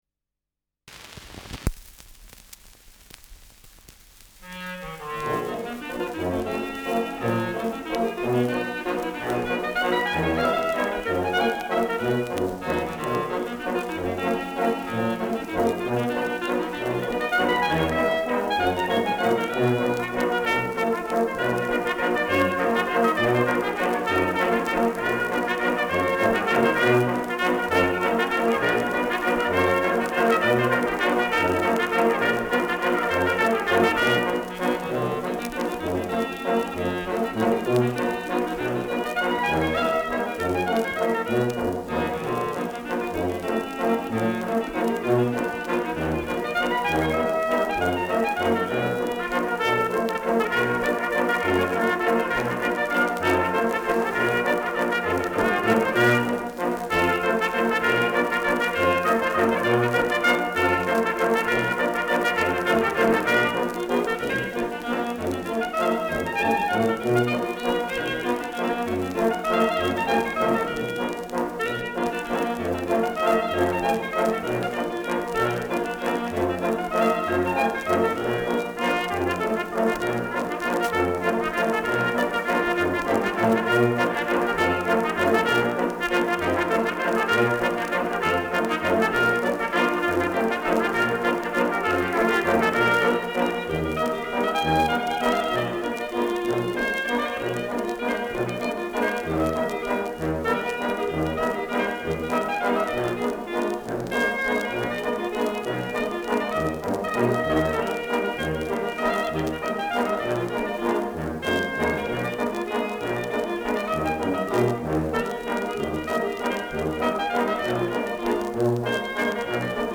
Schellackplatte
Stärkeres Grundknistern : Gelegentlich leichtes bis stärkeres Knacken
[München] (Aufnahmeort)